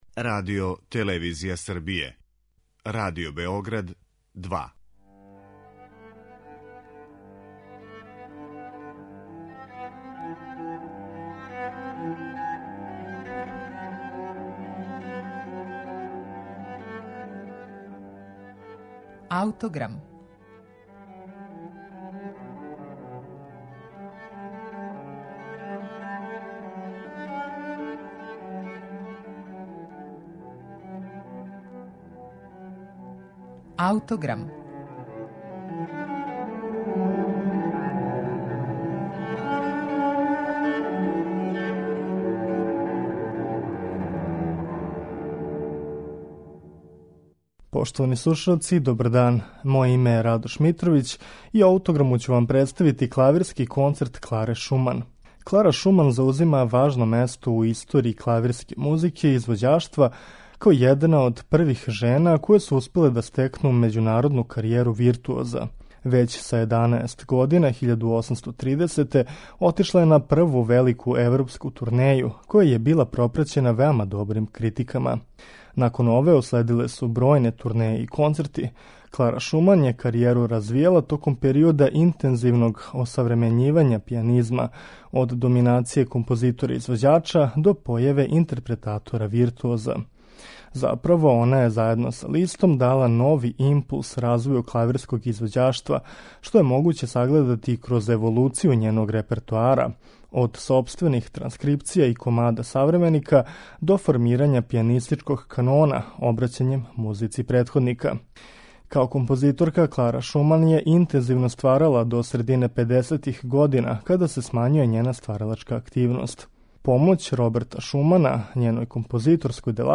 Први клавирски концерт Кларе Шуман